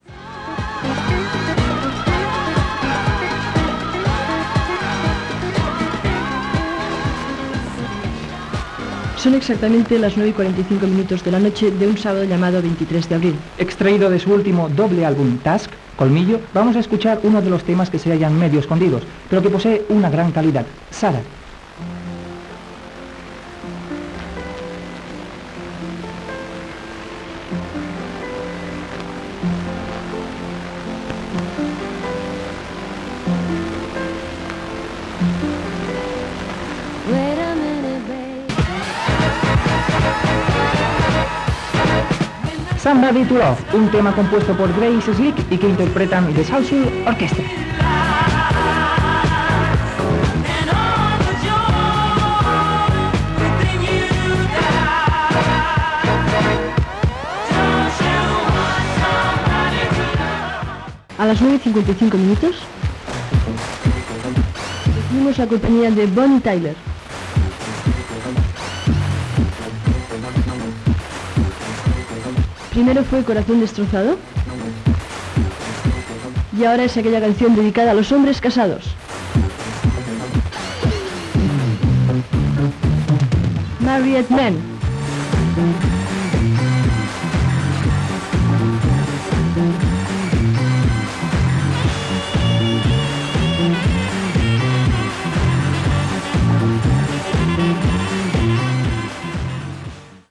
Data i presentació de dos temes musicals, hora i tema musical Gènere radiofònic Musical